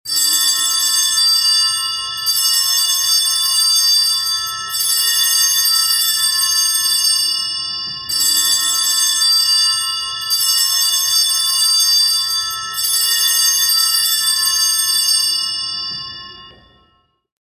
Category: Christian Ringtones